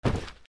カバンを落とす(mix用素材)
/ J｜フォーリー(布ずれ・動作) / J-10 ｜転ぶ　落ちる
服の上